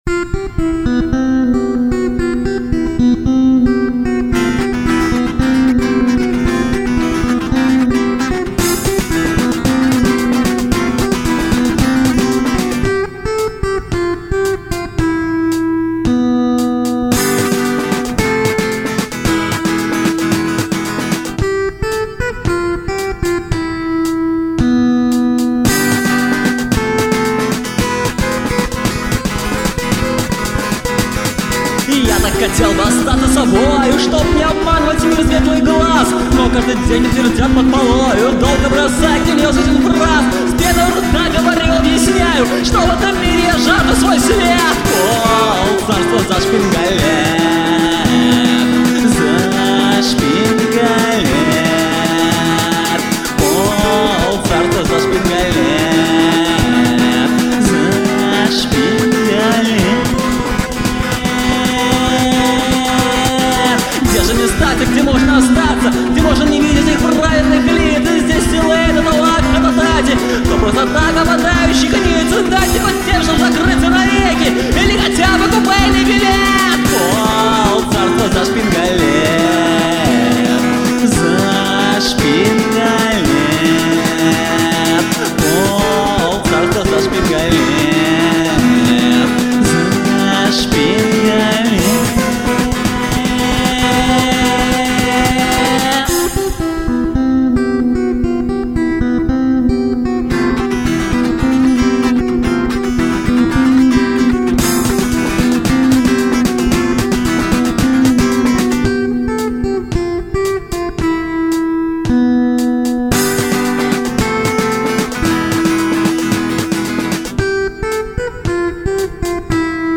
Музыкальный хостинг: /Альтернативная